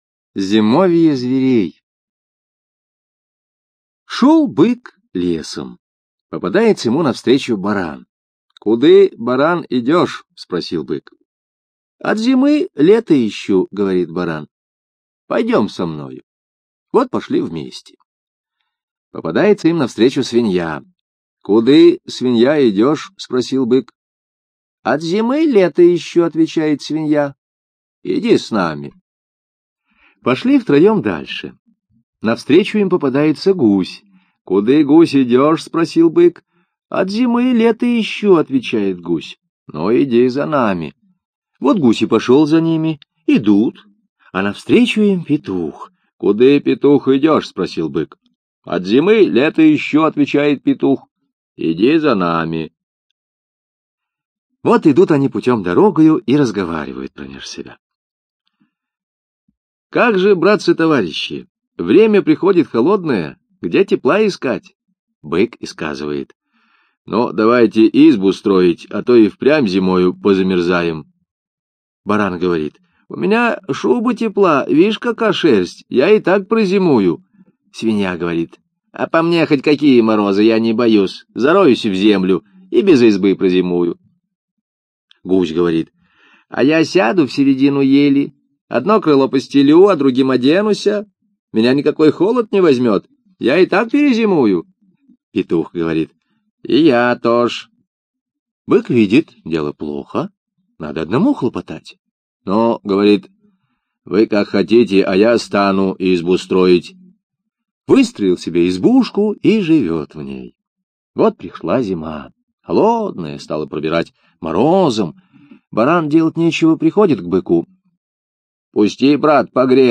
Зимовье зверей - русская народная аудиосказка - слушать онлайн